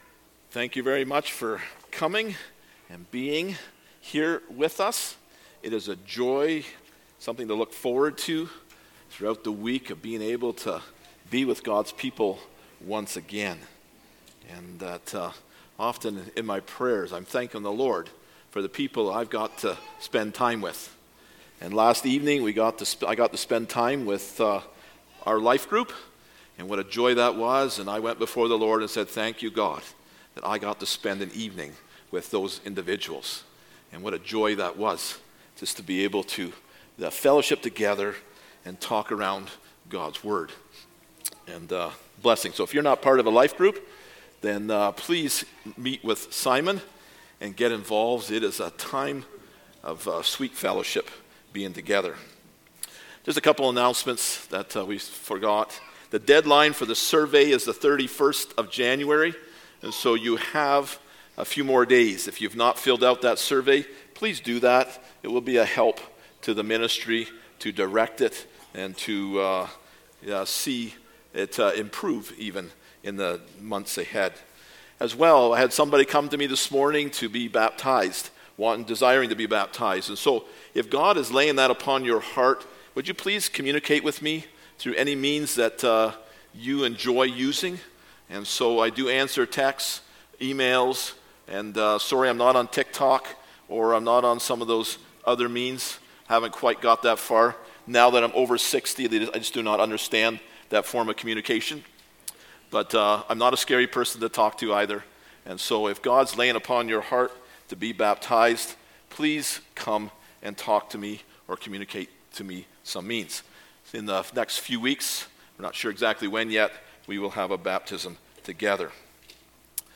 Passage: Ephesians 5:15-16 Service Type: Sunday Morning